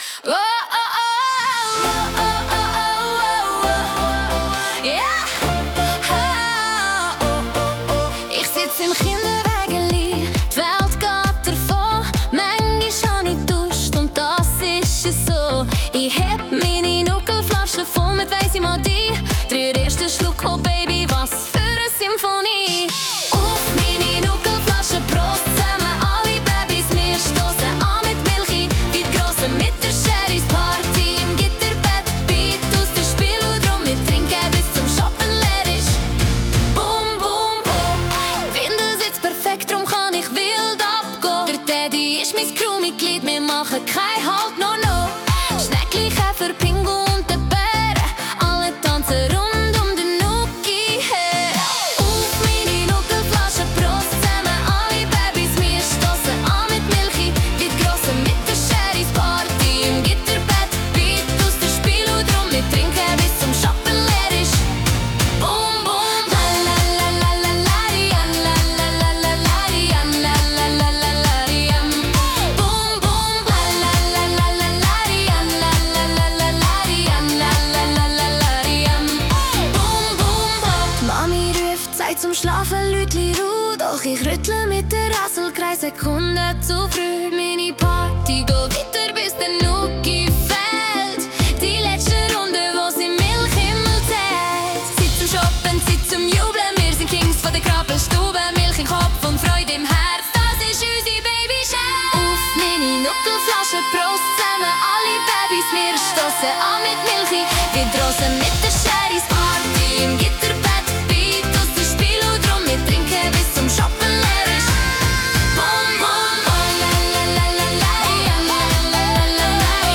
Genre: Disco Pop